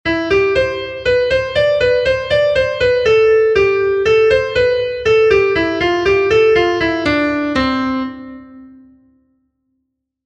Erlijiozkoa
Lauko txikia (hg) / Bi puntuko txikia (ip)
ABCB